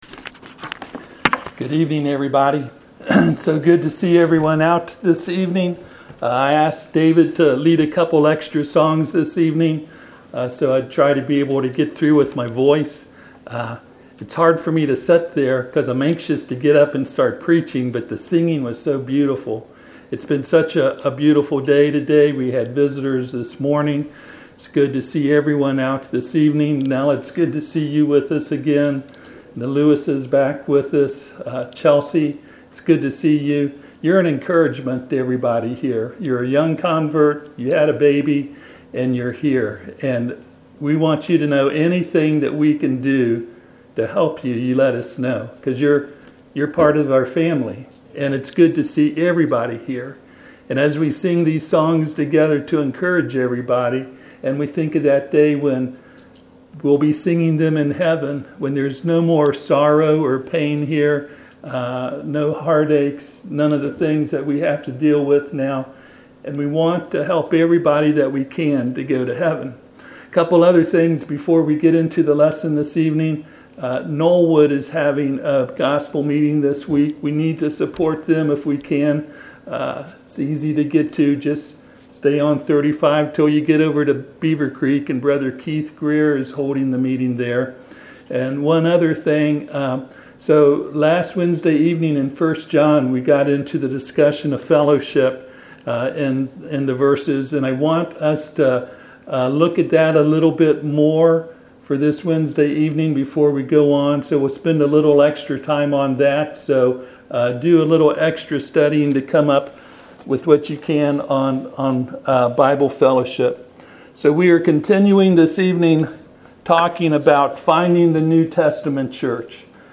Service Type: Sunday PM Topics: Authority , Church , Pattern